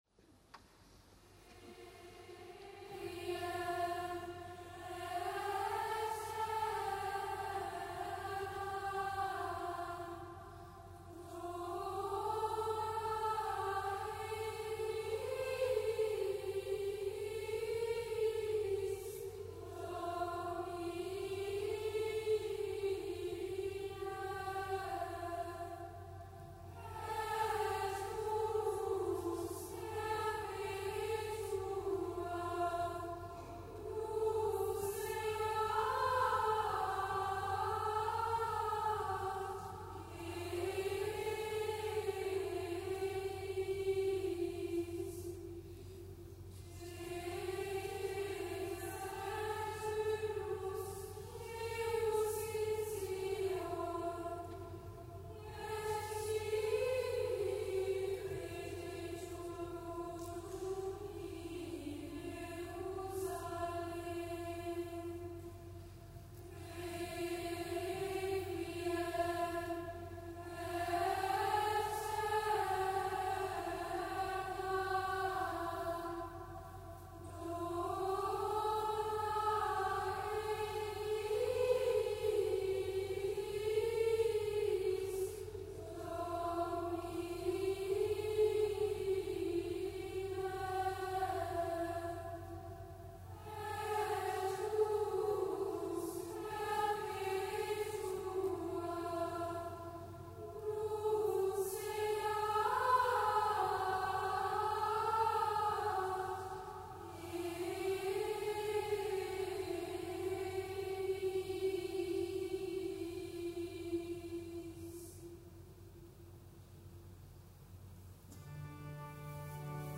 Pontifikalamt aus dem Kölner Dom an Allerseelen mit Erzbischof Rainer Maria Kardinal Woelki.